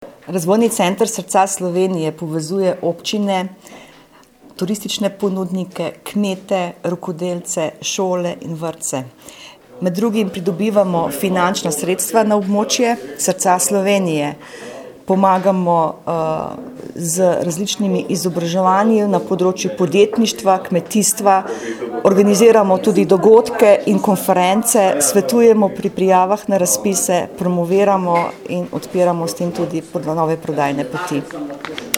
audio izjavo